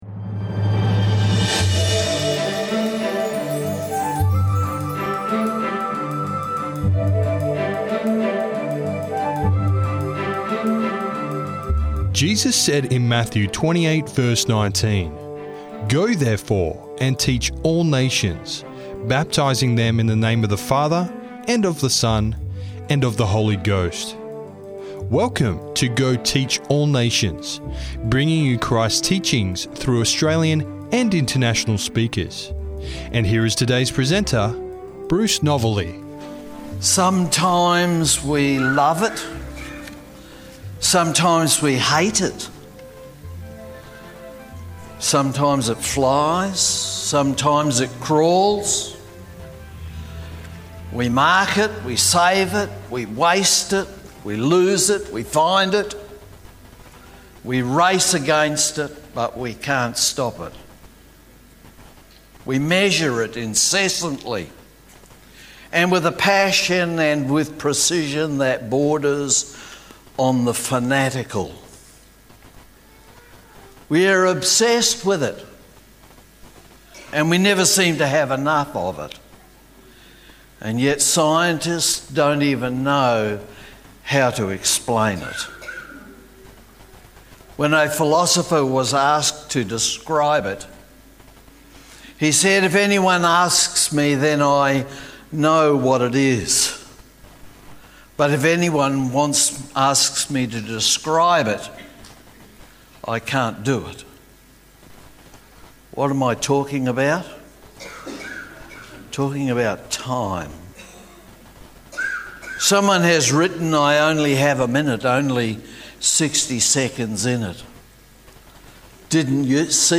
Living in God’s Kairos Time– Sermon Audio 2611